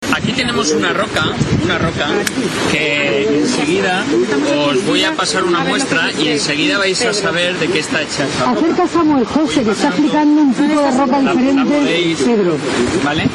en plena Playa de la Huerta